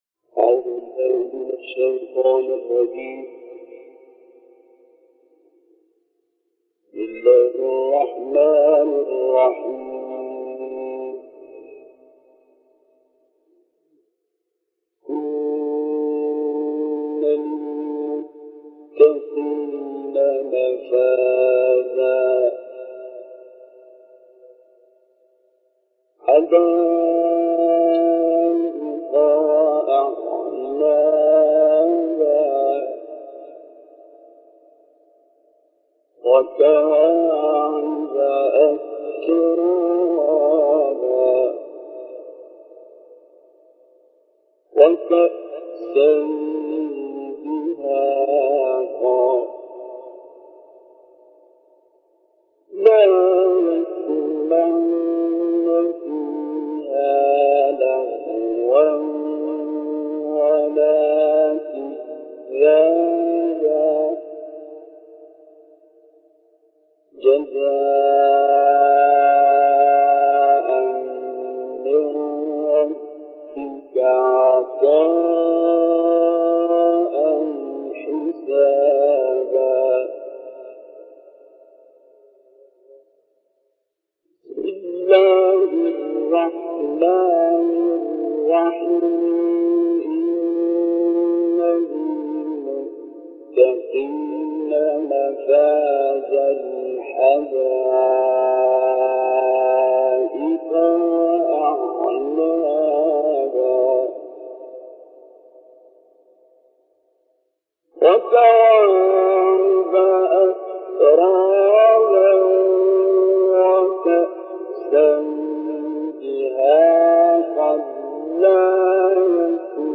تلاوت مشترک «عبدالباسط» و «رزیقی» در بغداد
گروه شبکه اجتماعی: تلاوت مشترکی از عبدالباسط محمد عبدالصمد و احمد الرزیقی که در سال 1986 میلادی در بغداد عراق اجرا شده است، به مناسبت سالروز فوت احمد الرزیقی ارائه می‌شود.
تلاوت مشترک
در این فایل صوتی عبدالباسط آیات 31 تا 40 سوره نباء و سوره انفطار را تلاوت می‌کند و احمد الرزیقی به ترتیل سوره‌های پایانی قرآن کریم(از سوره فیل تا سوره ناس) پرداخته است.